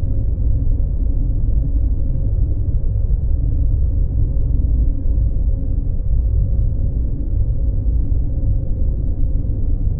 scpcb-godot/SFX/Ambient/Room ambience/lowdrone.ogg at bef423c5a75070d9c227c54f0fcb9b17fa33683c
lowdrone.ogg